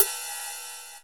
D2 RIDE-11-L.wav